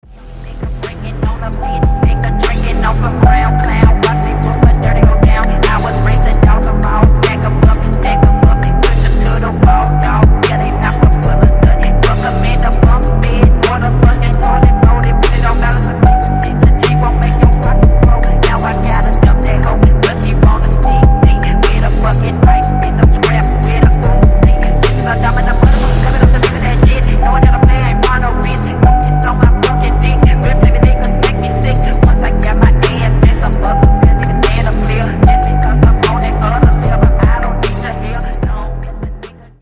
• Качество: 192, Stereo
атмосферные
без слов
vaporwave
Стиль: phonk / trap